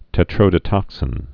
(tĕ-trōdə-tŏksĭn)